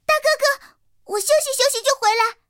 T-127小破修理语音.OGG